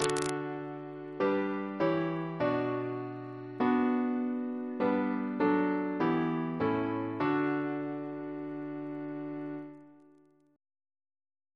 Single chant in D Composer: George A. Macfarren (1813-1887) Reference psalters: H1982: S181; PP/SNCB: 8